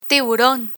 Click on the image to hear the Spanish pronunciation!